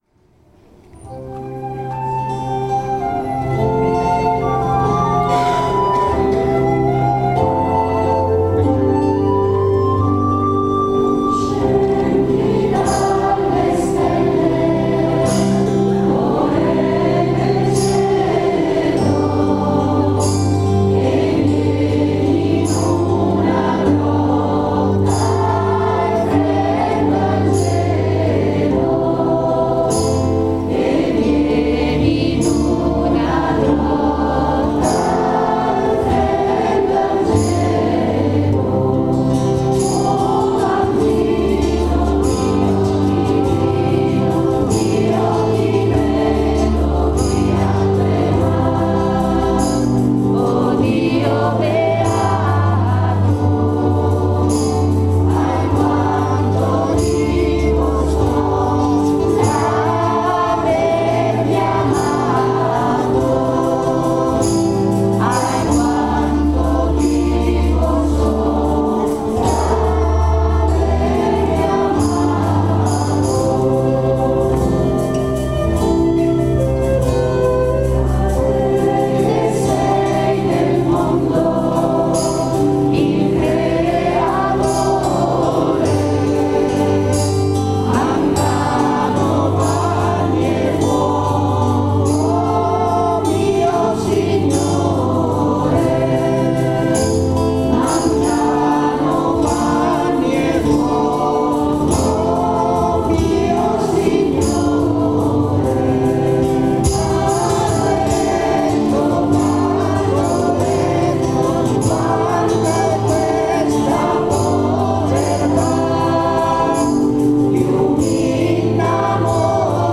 Si è tenuto nella Pieve di San Faustino il tradizionale concerto di natale con la partecipazione delle corali di Rubiera, San Faustino e Bagno dirette da